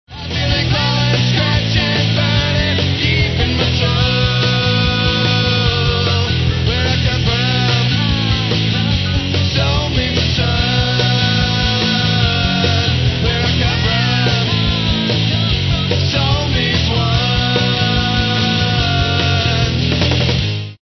rock
stereo